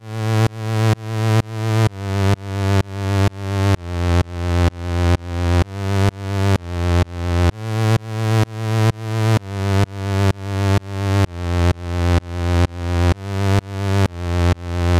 主厅合成器和弦
Tag: 128 bpm House Loops Synth Loops 2.52 MB wav Key : Unknown